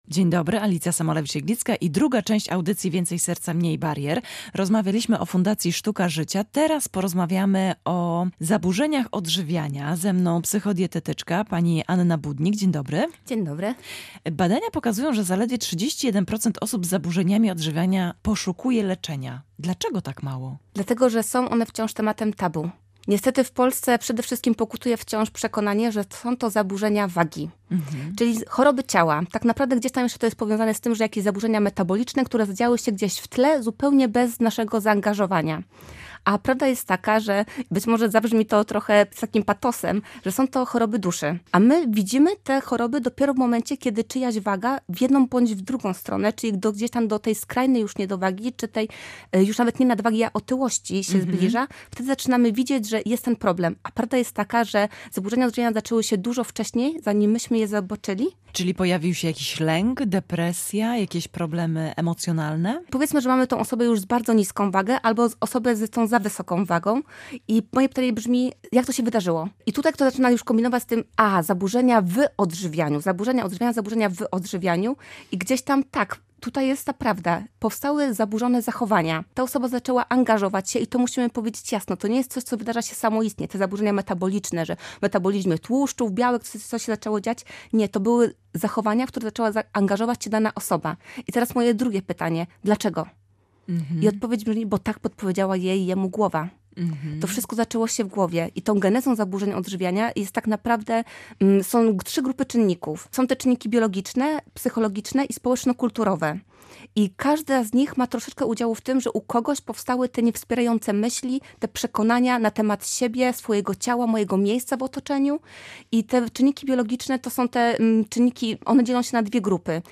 Rozmowy ze specjalistami